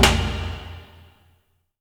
A#3 DRUMS0FR.wav